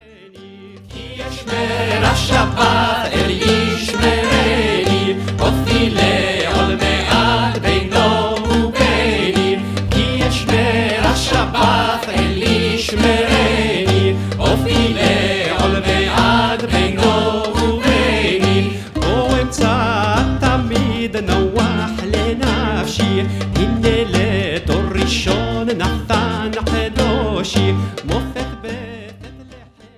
Folk (Iraq)